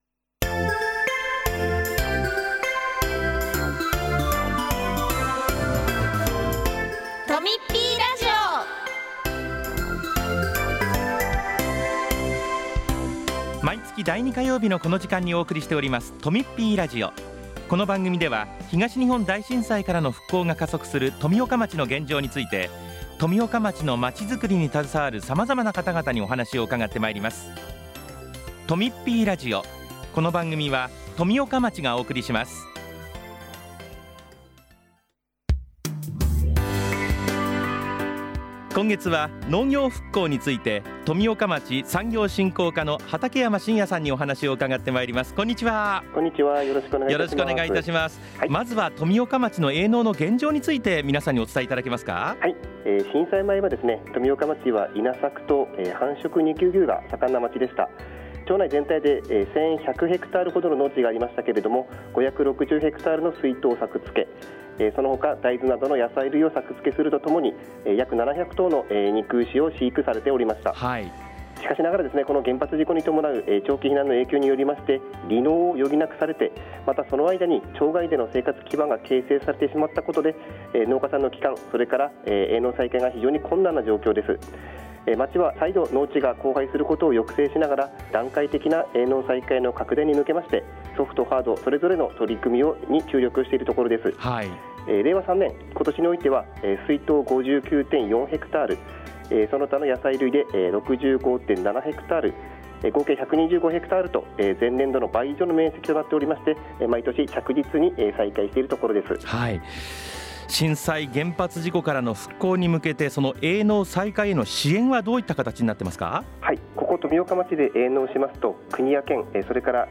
8月10日（火曜日）に放送したとみっぴーラジオをお聴きいただけます。
今月は「農業復興」をテーマに、町の取組みなどについて担当者がお話をします。